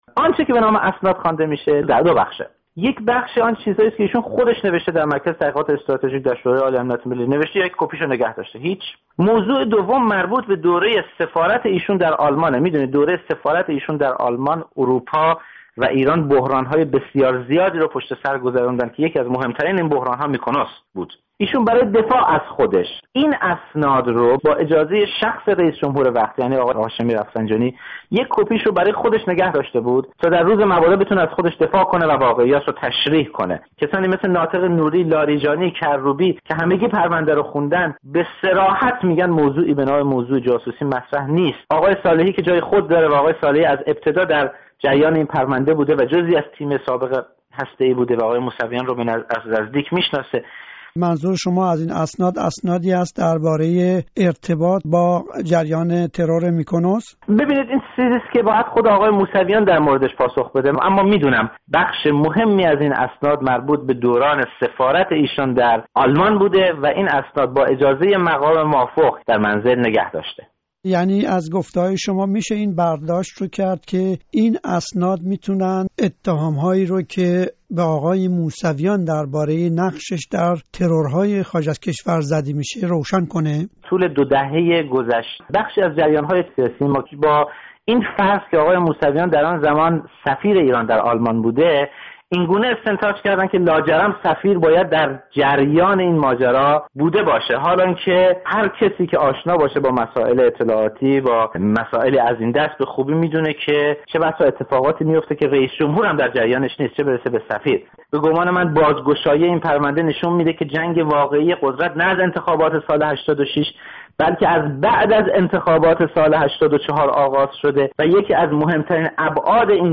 گفت و گوی رادیو فردا